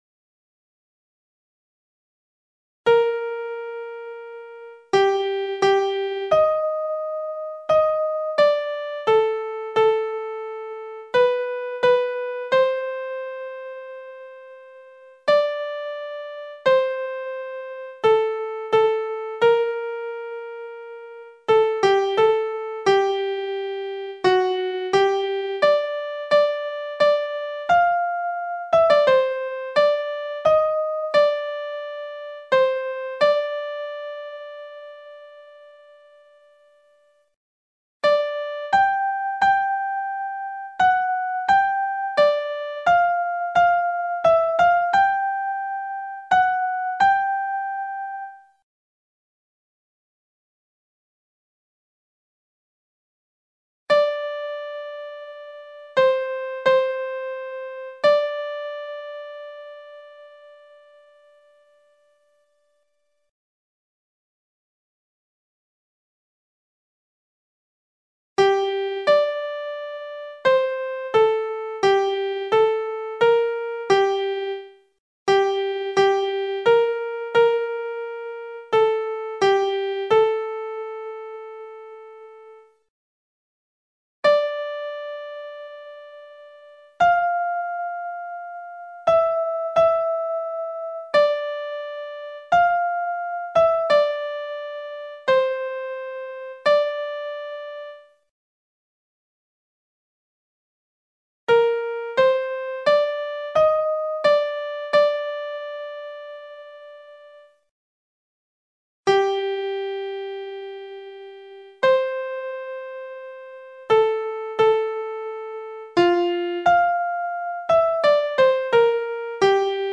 MIDI Tenores